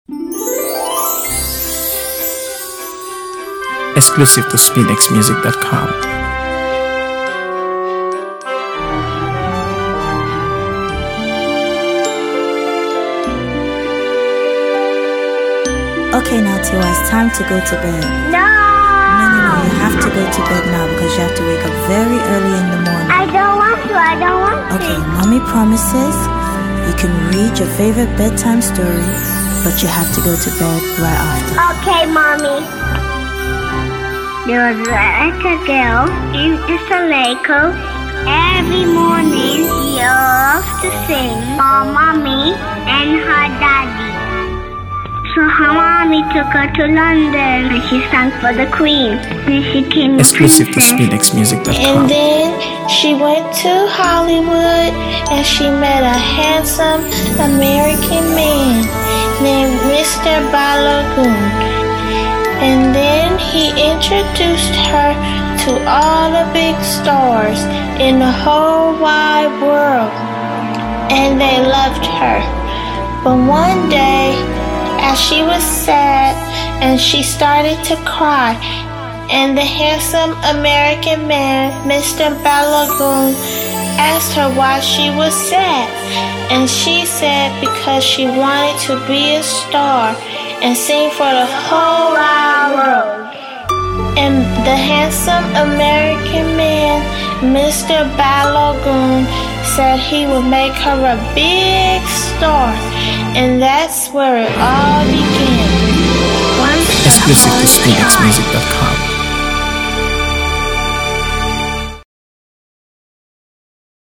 AfroBeats | AfroBeats songs
blending smooth rhythms with engaging storytelling.